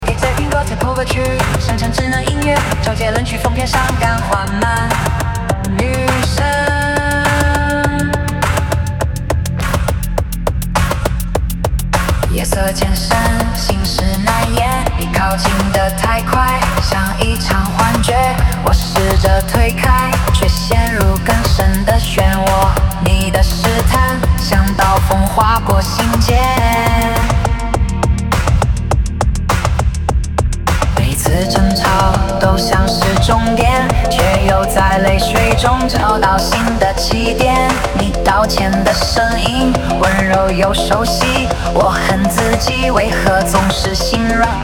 给这篇歌词谱个曲，生成智能音乐，周杰伦曲风偏伤感缓慢，女声
人工智能生成式歌曲